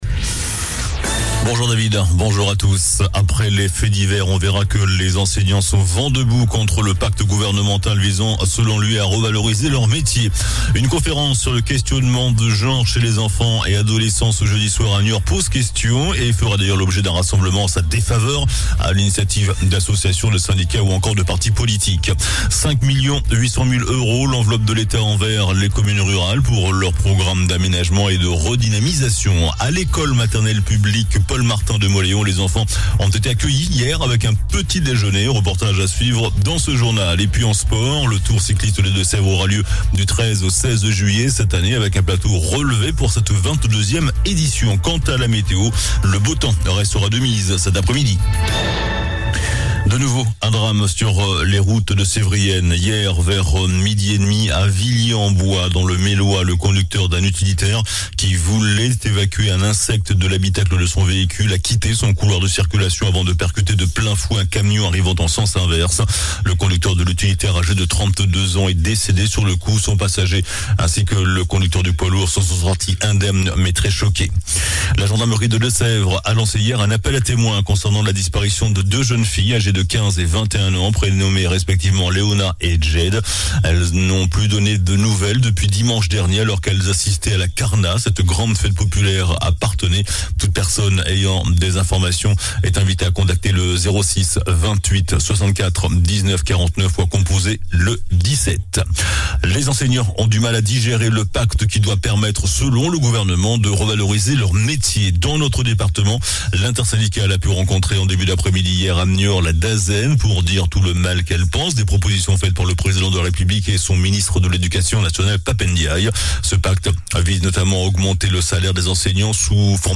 JOURNAL DU JEUDI 01 JUIN ( MIDI )